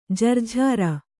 ♪ jarjhāra